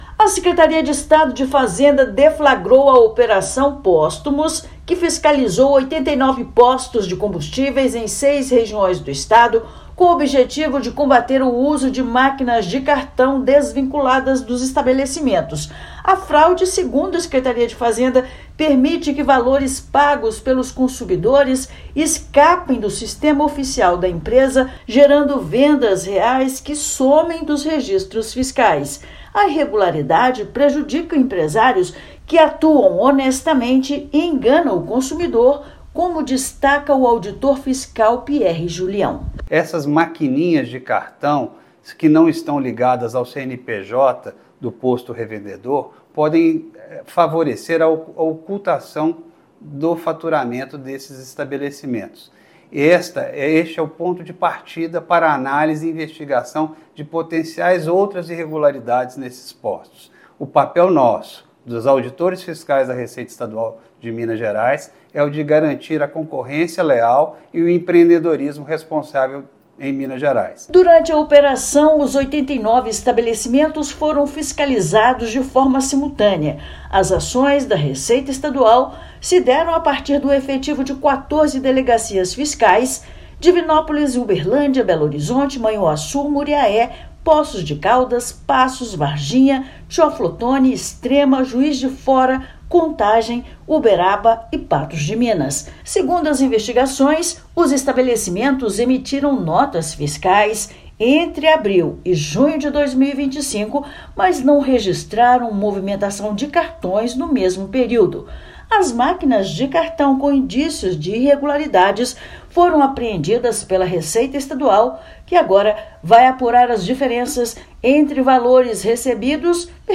Objetivo é identificar e coibir o uso irregular dos equipamentos com a finalidade de sonegar o ICMS. Ouça matéria de rádio.